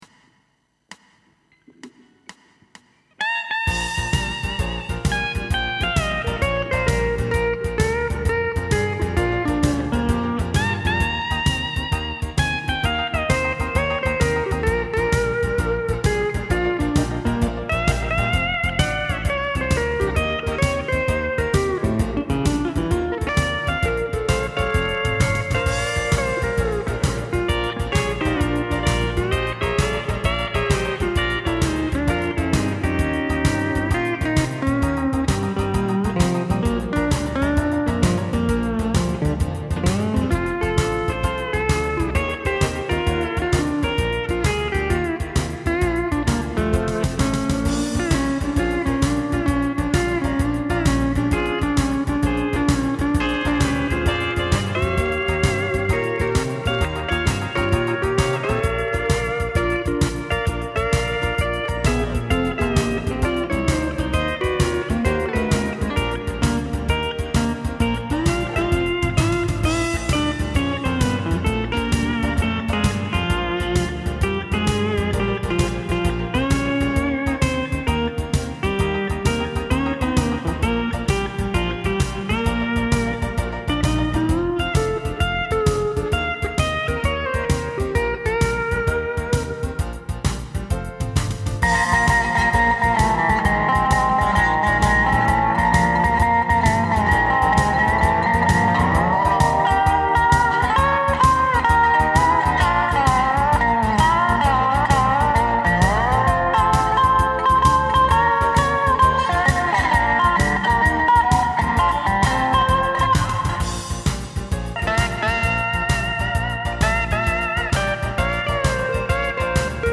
A blues improvisation recorded in 2008
Pedal Steel Guitar
Dobro